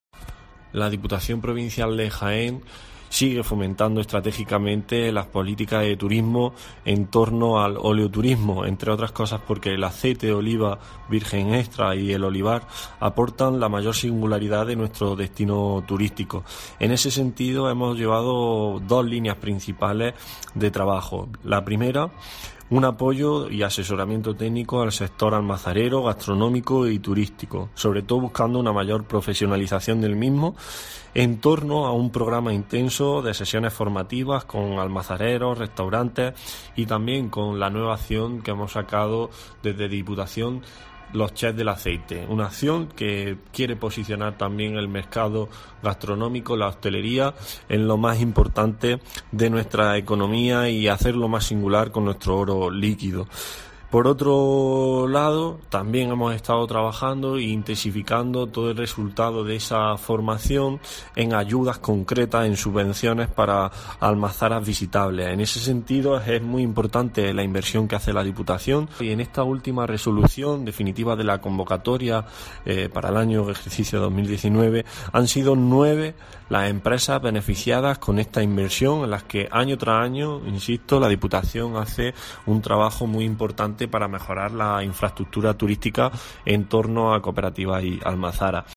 Francisco Javier Lozano, diputado de Turismo, habla sobre estas ayudas al óleoturismo 1